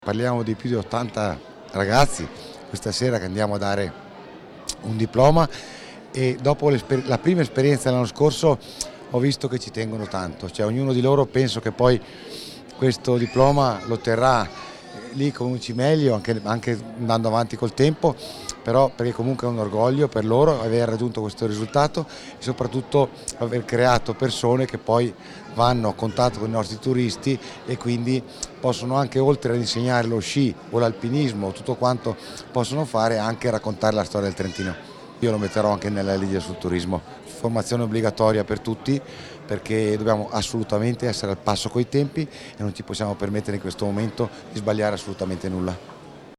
Nella Sala Marangonerie del Castello del Buonconsiglio consegnati i diplomi ai professionisti della montagna